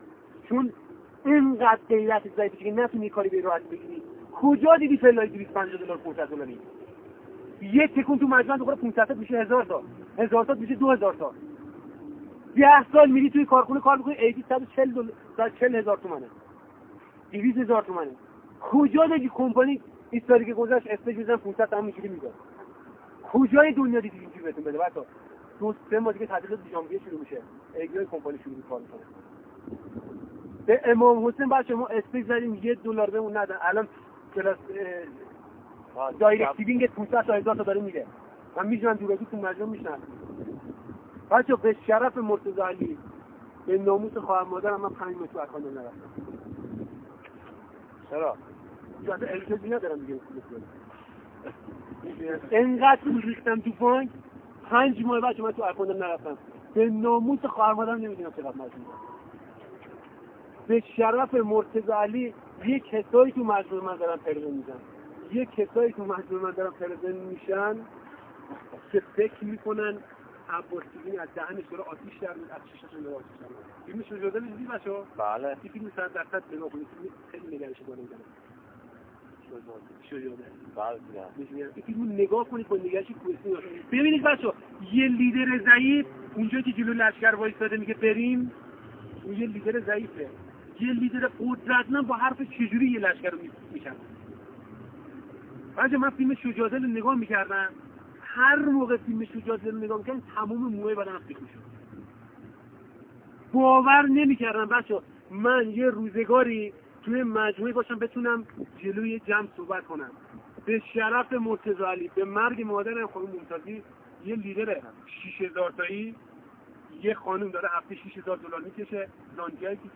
دانلود بخشی از ویس
دمو ویس جلسه لیدر